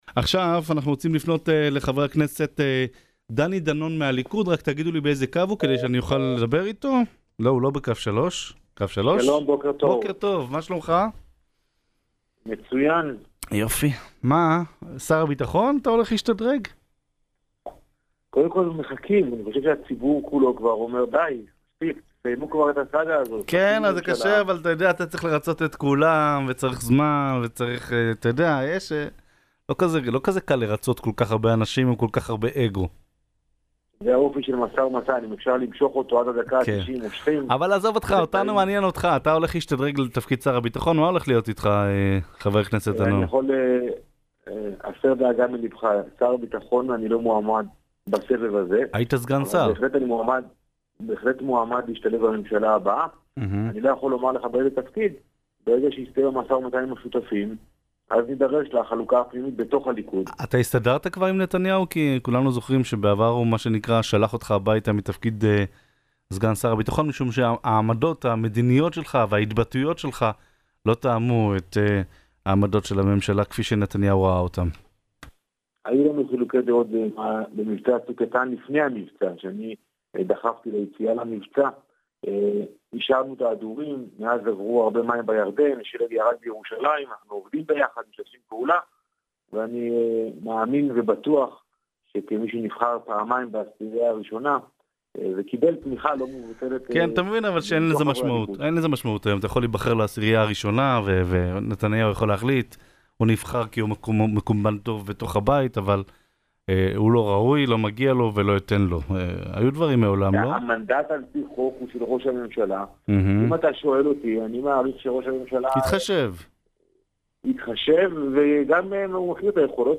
ראיון-דני-דנון.mp3